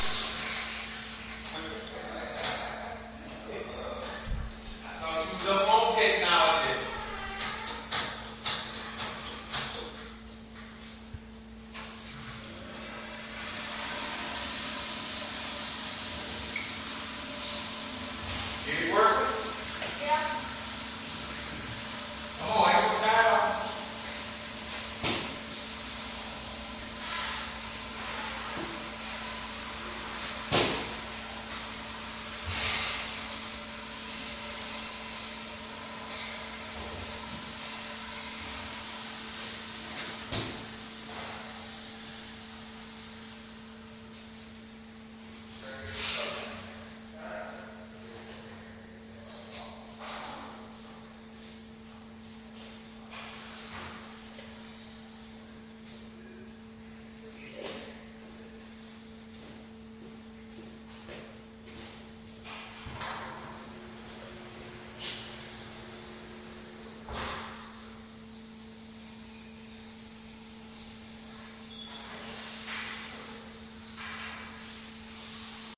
描述：用DS40录制的乔治亚游客中心内的音响效果很好的浴室，并在Wavosaur中进行编辑。
Tag: 现场录音 公路旅行